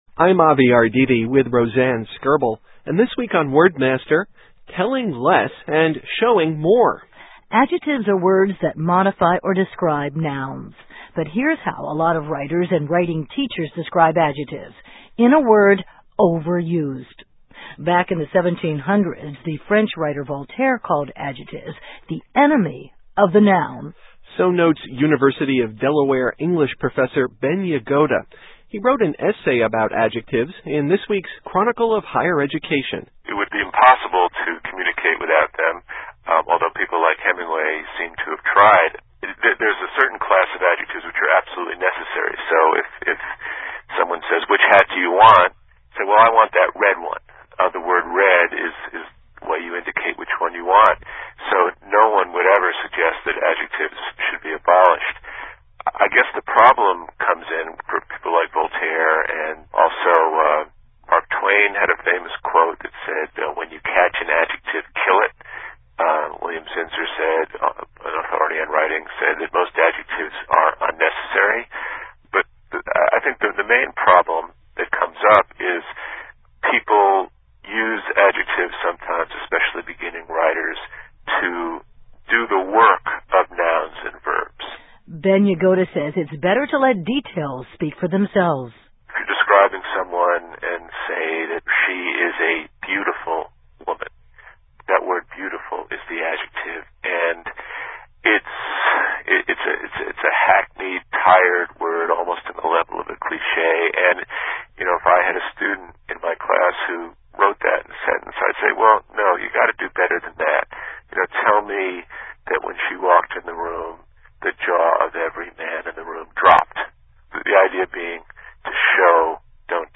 Broadcast: February 26, 2004